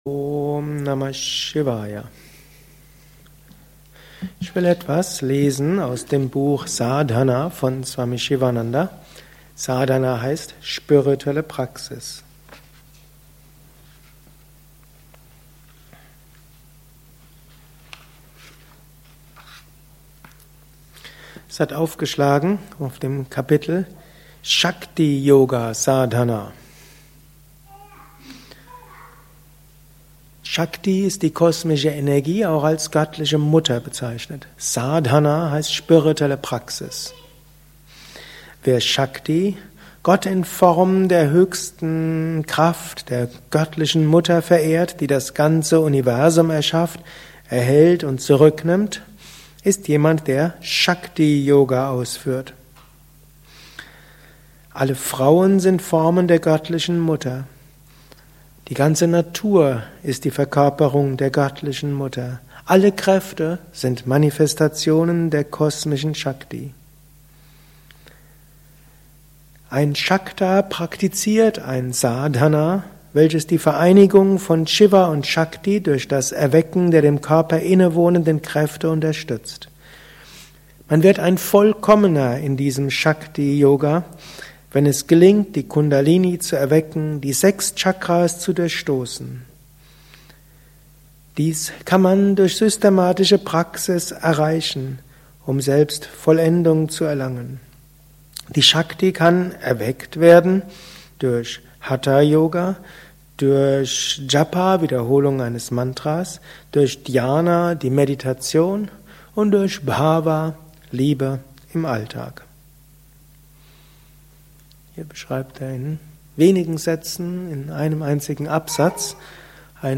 Gelesen im Anschluss nach einer Meditation im Haus Yoga Vidya Bad Meinberg.
Lausche einem Vortrag über Shakti Yoga Sadhana